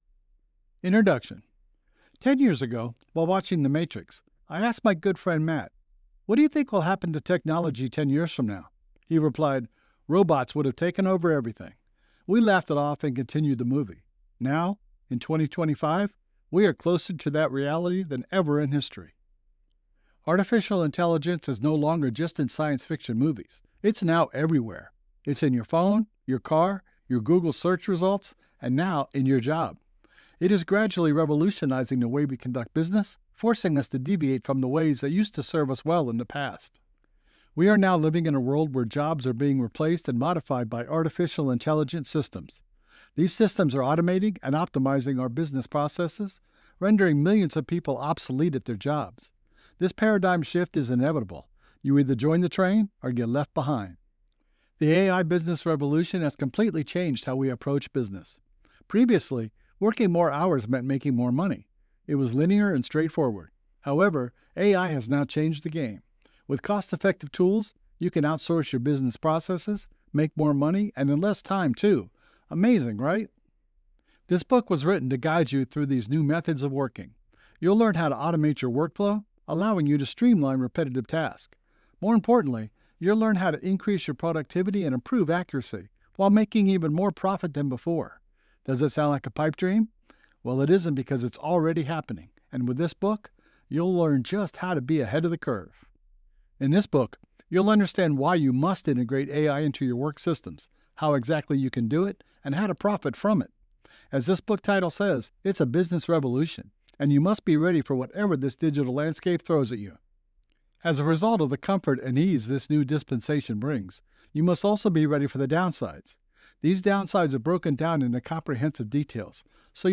Male
I have a Deep, Friendly, Relatable, Professional voice that can complement almost any project and make it shine!
Audiobooks
Business Ai Audiobook